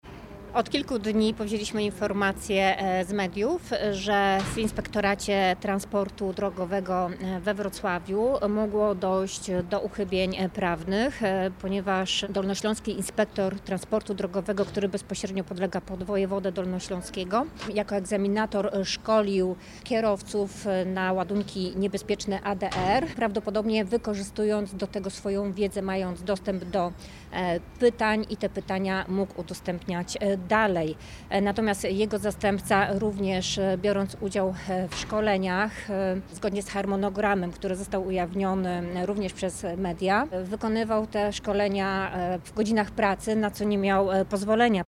Z ustaleń kontrolnych wynika, że zarówno Inspektor Transportu Drogowego, jak i jego zastępca, mieli przeprowadzać szkolenia poza obowiązkowymi godzinami pracy. Mówi Agnieszka Soin, poseł na Sejm.